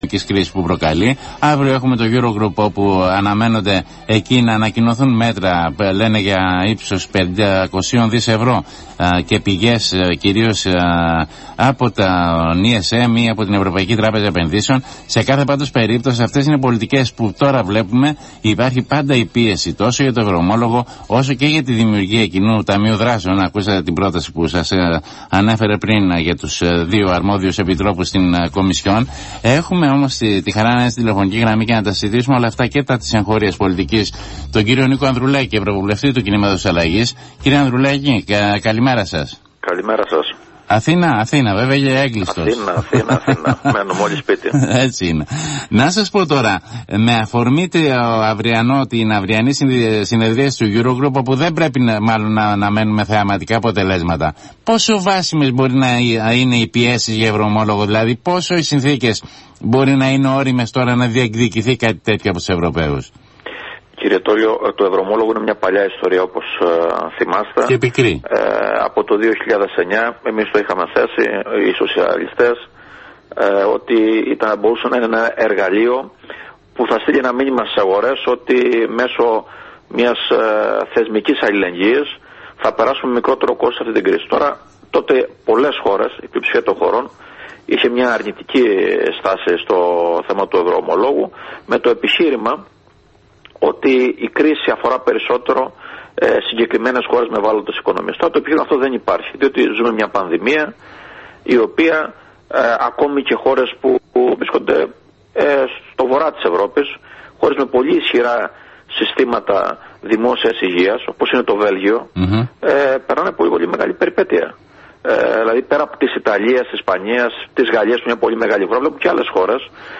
μιλώντας στον Αθήνα 9.84